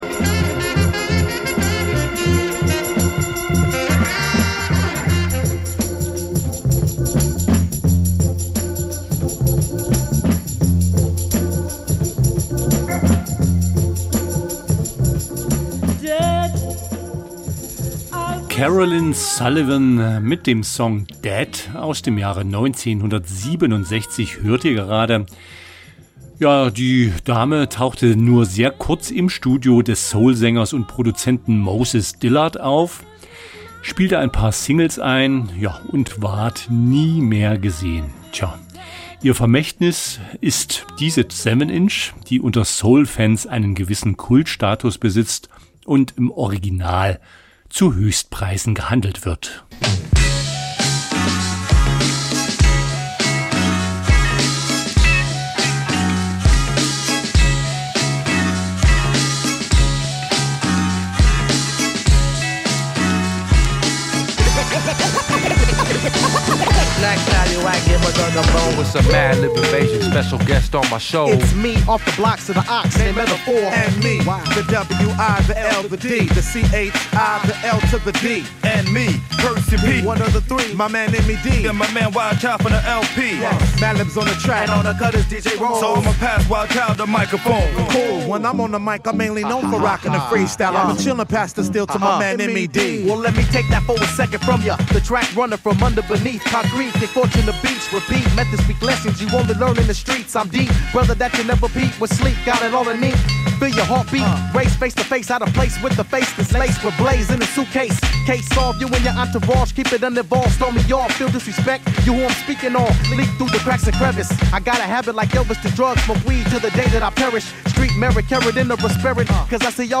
Hinter WHAT ABOUT BREAKS? verbergen sich unter dem alles �berspannenden Dach der Hip Hop Kultur neben den vorher genannten Stilrichtungen auch BLUES, LATIN, ELECTRO, REGGAE und POP mit jeder Menge Hintergrundinfos zur Musik und den K�nstlern.